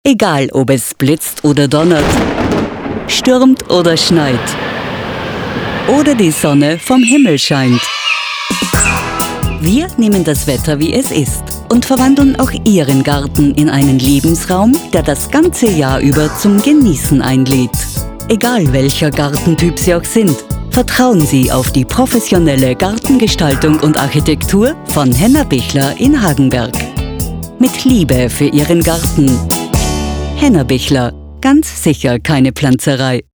Die Stimme ist bekannt aus zahlreichen Werbeproduktionen, sehr variantenreich von seriös, entspannt erzählerisch bis frech, sinnlich, warm.
Sprechprobe: Werbung (Muttersprache):
She can sound deep and trustworthy but also very energetic.